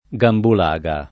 [ g ambul #g a ]